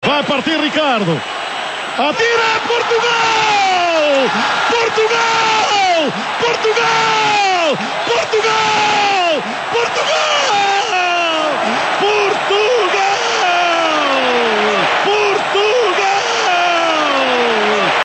Jorge Perestrelo relata a vitória portuguesa frente a Inglaterra nos penáltis durante os quartos-de-final de que competição?
Jorge Perestrelo dá voz a uma das mais emocionantes partidas do Euro 2004: o jogo entre Portugal e Inglaterra, nos quartos-de-final da prova.